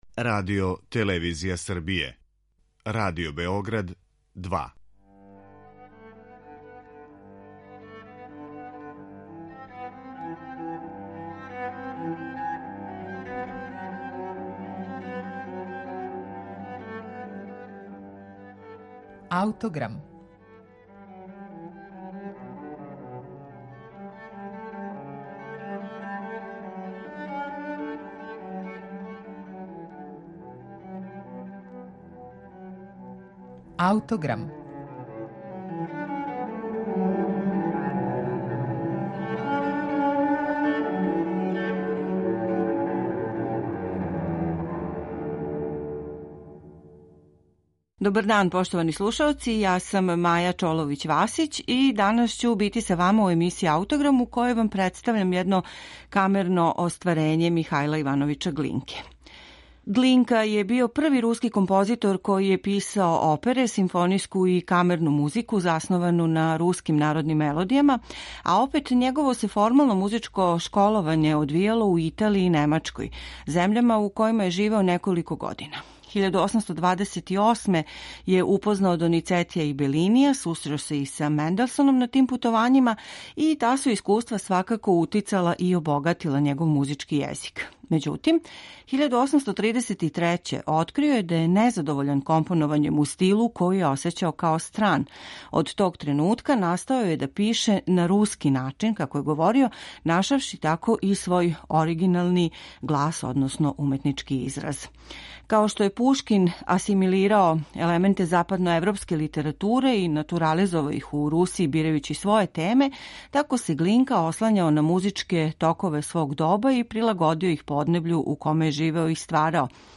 Глинка је ову камерну композицију написао за необичан и јединствен састав ‒ за кларинет, фагот и клавир ‒ доделивши дувачким инструментима деонице које својом певљивошћу доносе јасну асоцијацију на његову вокалну музику.